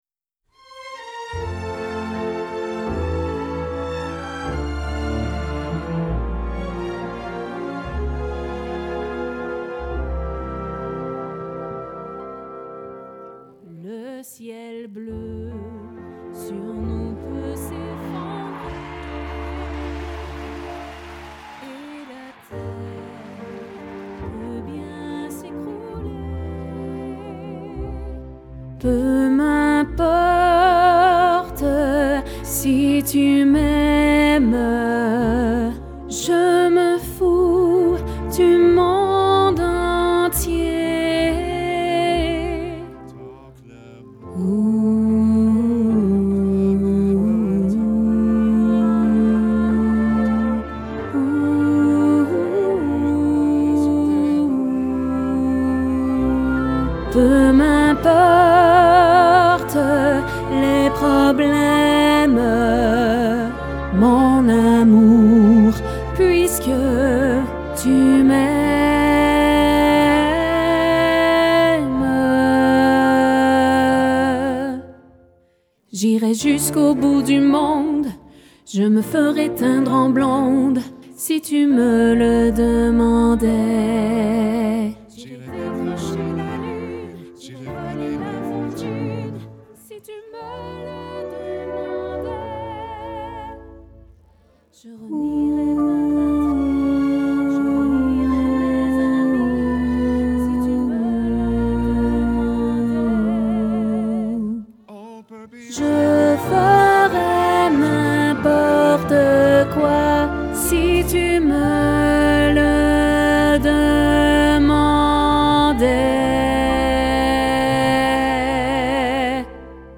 Ténors
LHymne-a-lamour-Tenor.mp3